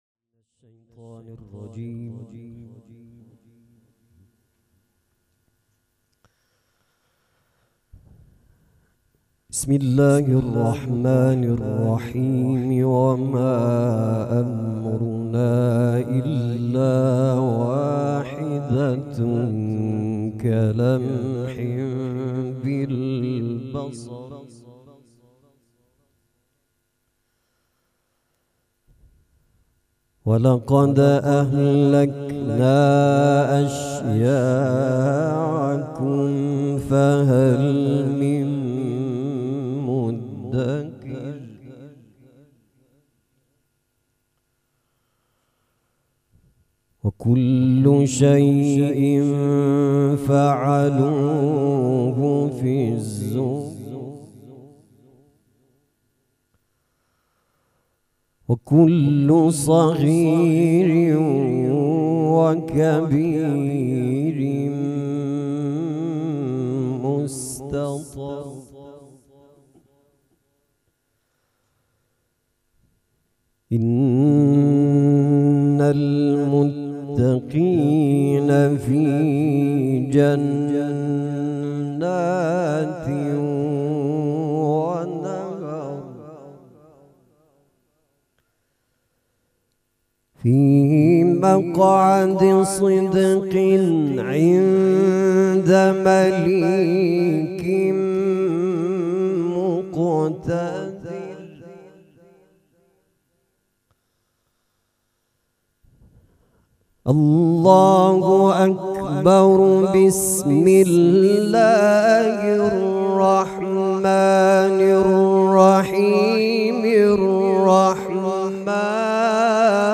قرائت قرآن کریم
عزاداری حضرت زهرا سلام الله علیها ۱۳۹۹
مراسم عزاداری شب شهادت حضرت زهرا (س)